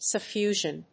音節suf・fu・sion 発音記号・読み方
/səfjúːʒən(米国英語)/